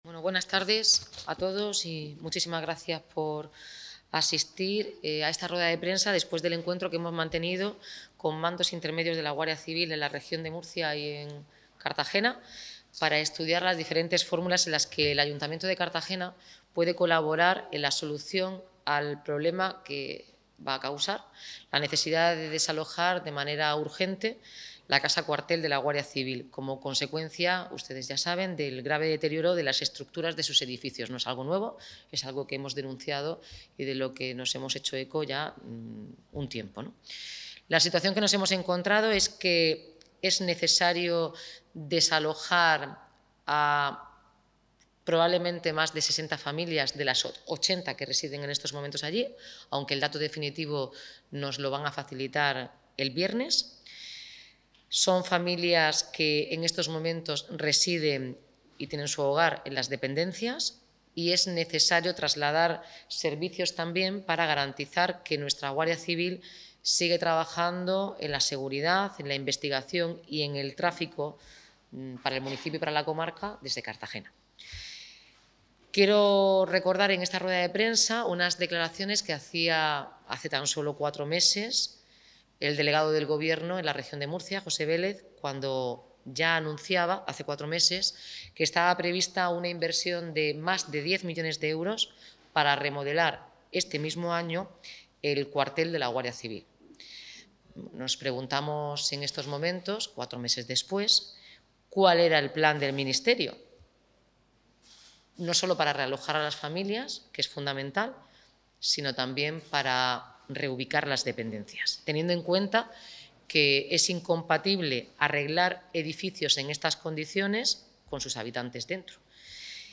Enlace a Declaraciones de la alcaldesa, Noelia Arroyo.